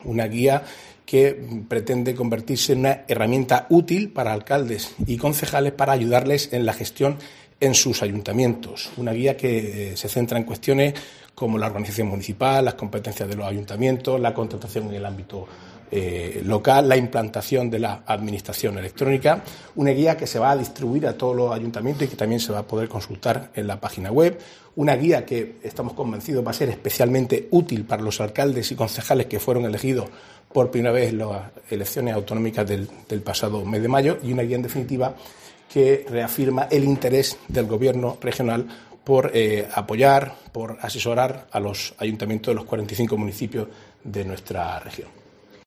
Marcos Ortuño, consejero de Presidencia, Portavocía y Acción Exterior